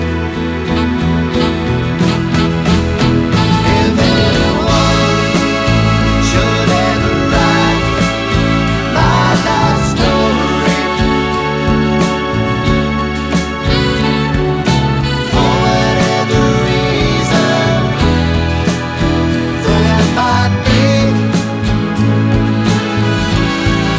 No Backing Vocals Soul / Motown 3:48 Buy £1.50